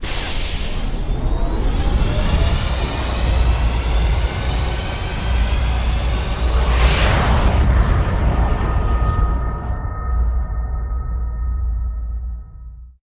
CosmicRageSounds/launch5.ogg_919c8f34 at 4f151c074f69b27e5ec5f93e28675c0d1e9f0a66